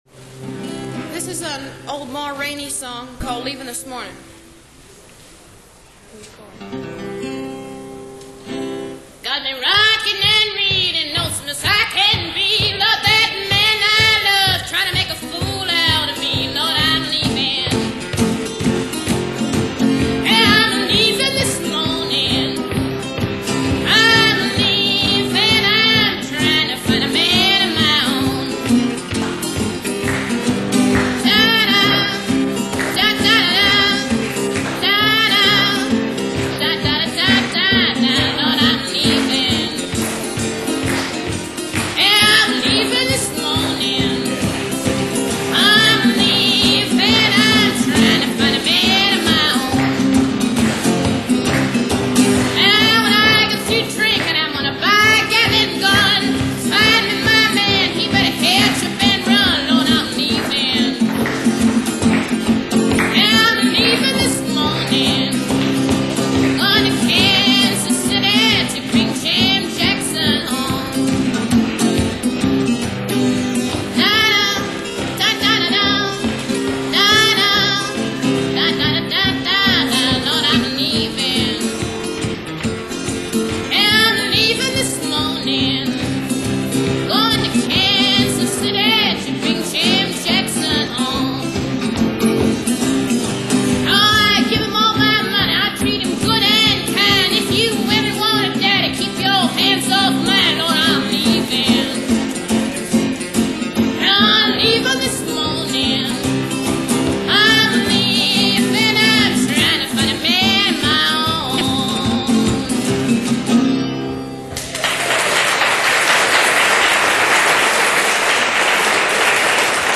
mini-concert edition.
It’s straight acoustic; no drums, no crowds.
Rural Blues